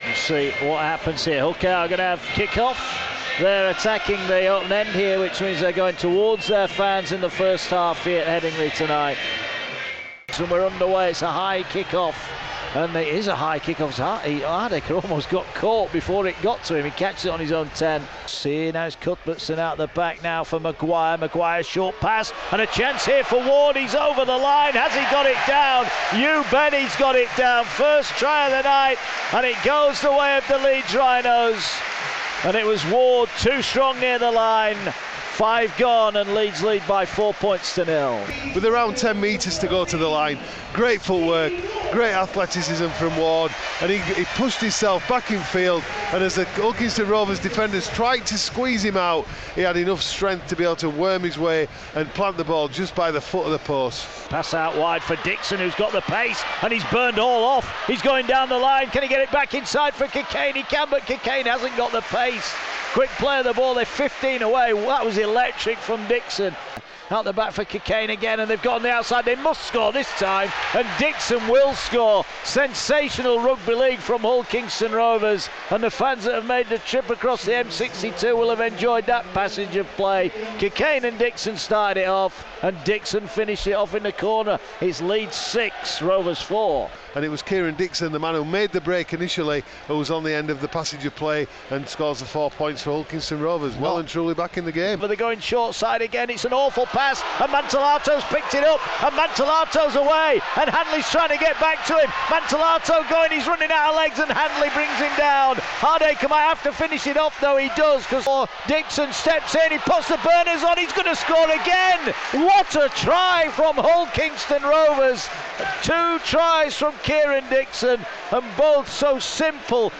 Highlights of the match between Leeds Rhinos & Hull KR on Radio Yorkshire, commentary